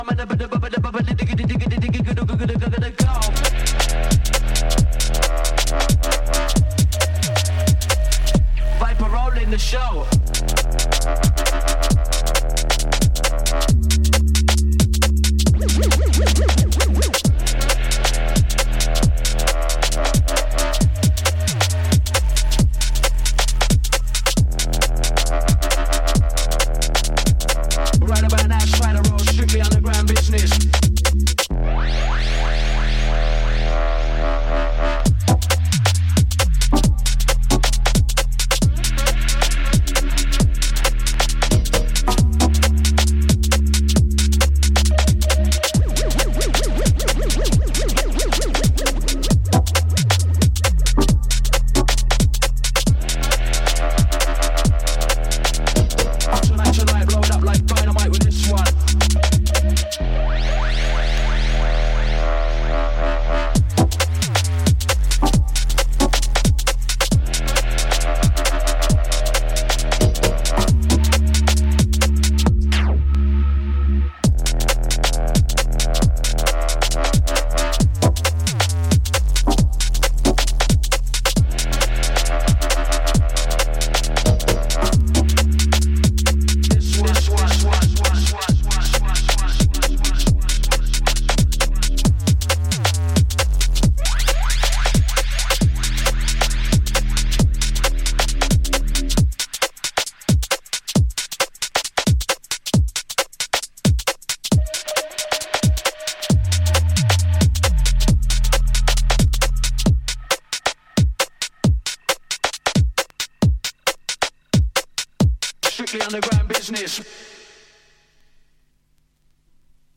空間を揺らす重低音サブベースとトリッキーなラップが印象的な135BPMの2ステップ